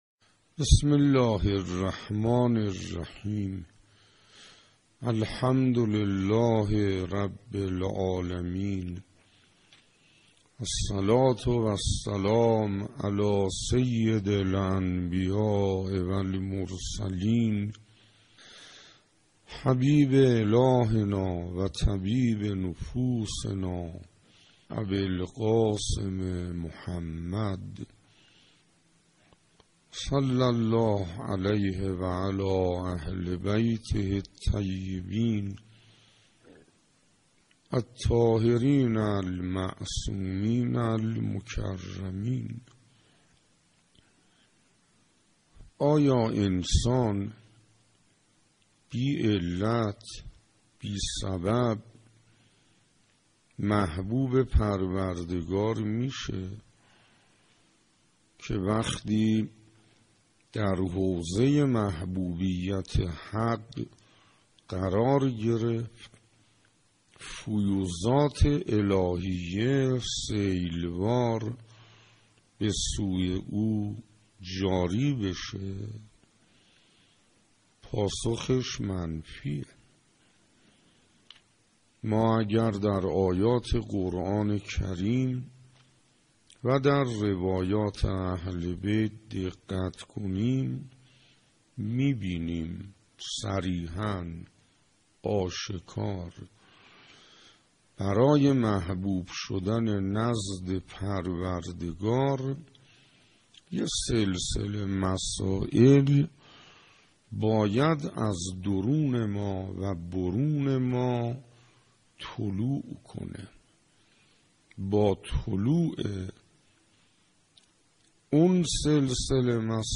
سخنرانی حجت الاسلام انصاریان با موضوع توبه، از عوامل جلب رحمت الهی